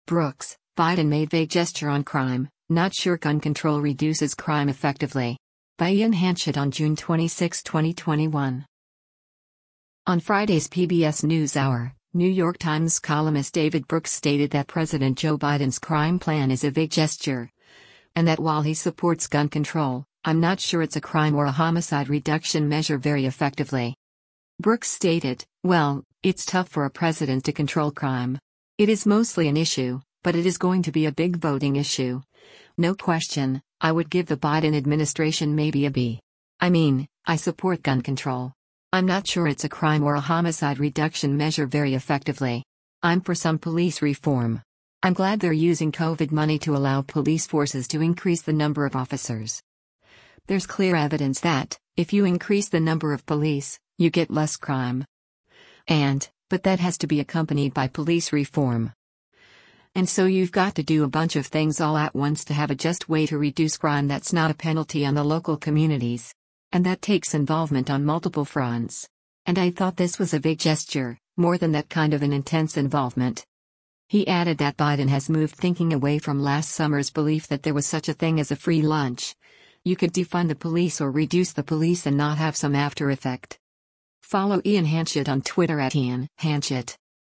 On Friday’s “PBS NewsHour,” New York Times columnist David Brooks stated that President Joe Biden’s crime plan is “a vague gesture,” and that while he supports gun control, “I’m not sure it’s a crime or a homicide reduction measure very effectively.”